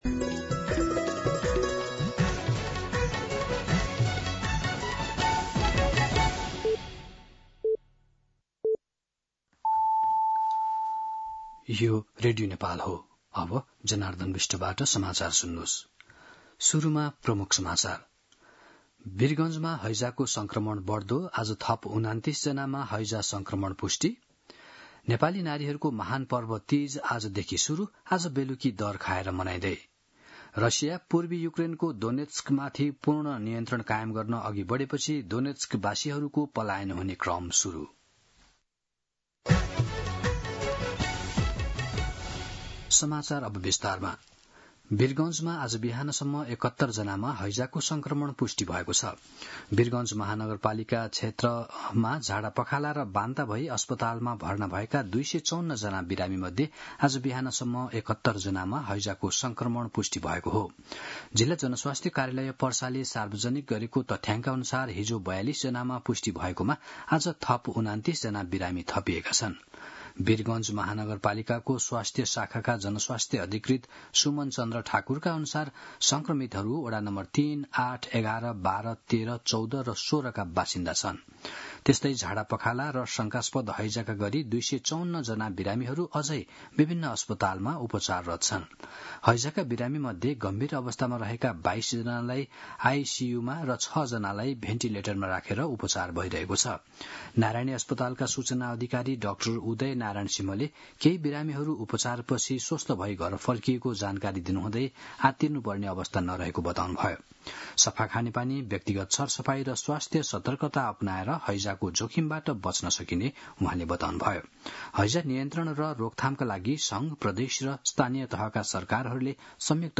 दिउँसो ३ बजेको नेपाली समाचार : ९ भदौ , २०८२
3-pm-News-09.mp3